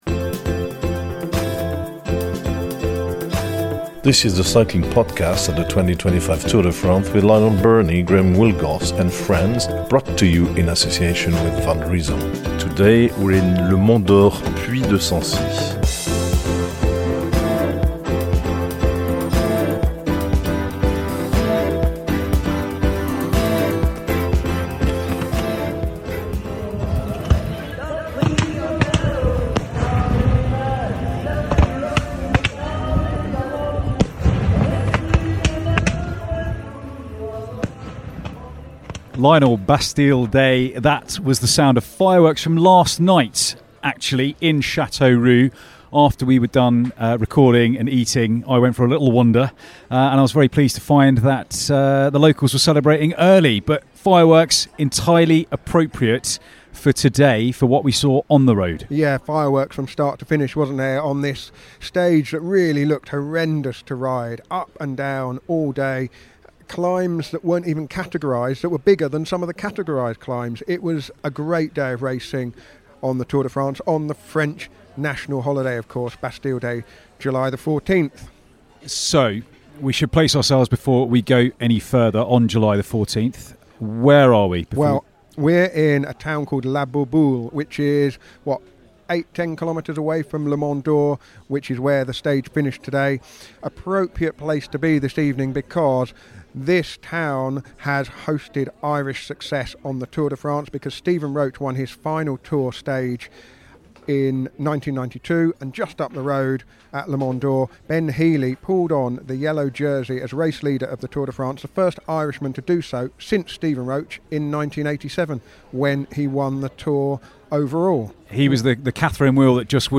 We’re back on the road for the 13th time with daily episodes recorded at the heart of the world’s biggest race. Our nightly episodes feature race analysis, interviews and plenty of French flavour.